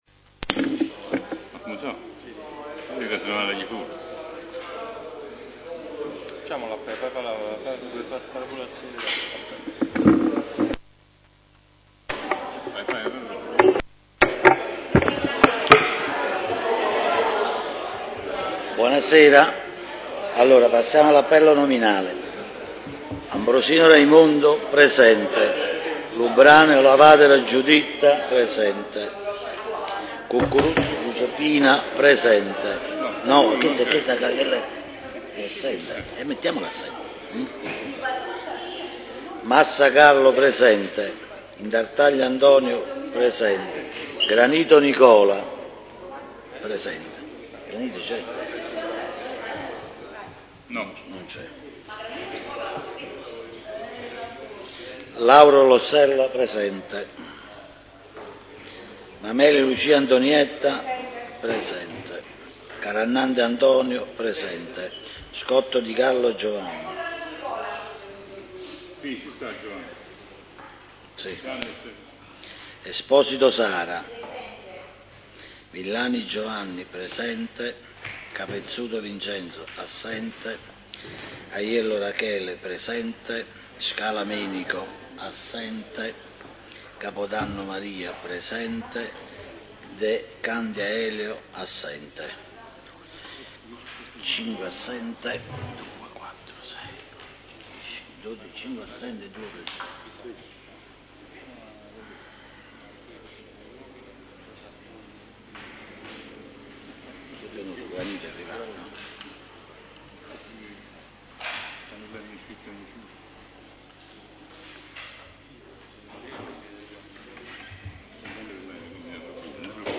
Procida: Pubblicato nella Radio il Consiglio Comunale del 7 agosto ’15
Il presidente del Consiglio Comunale, avv. Antonio Intartaglia, ha convocato il Civico Consesso in seduta straordinaria per il prossimo 7 agosto alle ore 19,00 presso la sala “V. Parascandola” del Comune in via Libertà. Sostanzialmente tre gli argomenti all’ordine del giorno: 1) Surroga Consigliere Comunale Cucurullo Giuseppina – Convalida Consigliere subentrante; 2) Schema di ripiano del maggiore disavanzo di amministrazione, derivante dal riaccertamento dei residui attivi e passivi; 3) Modifica ed Integrazione Statuto Comunale.